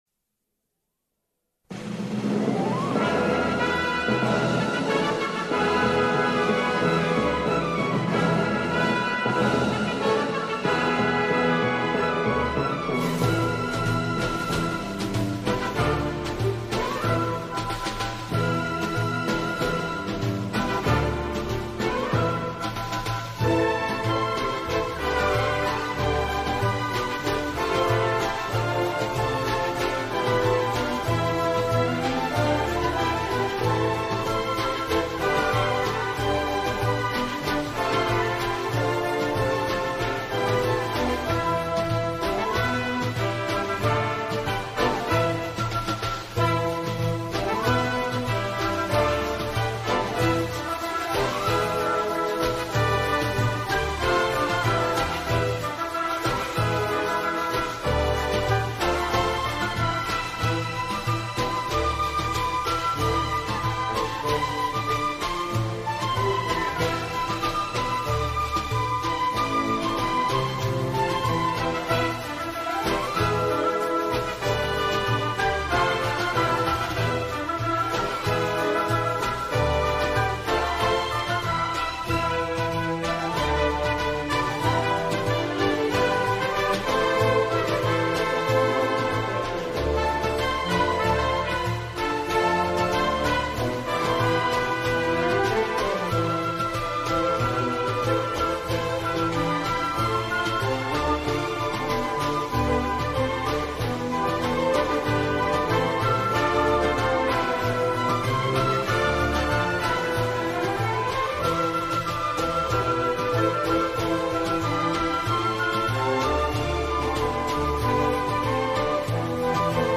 سرودهای ورزشی
بی‌کلام